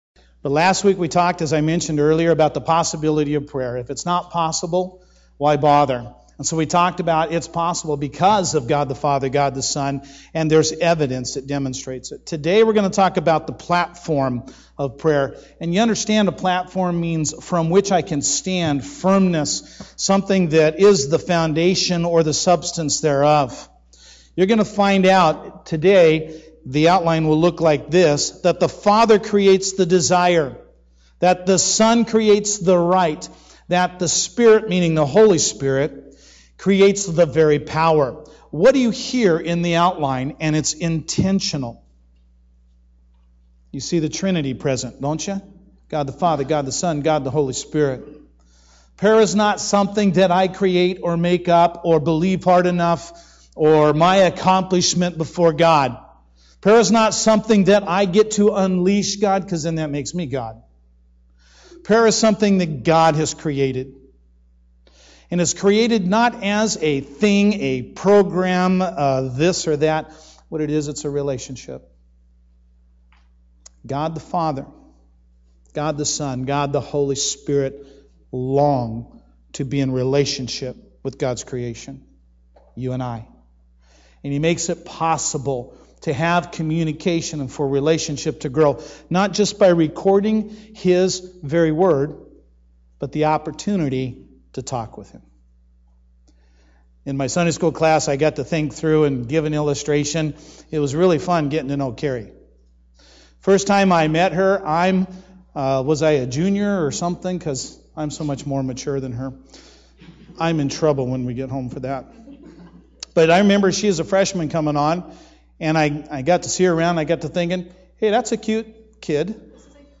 Sermons Archive - North Mason Bible Church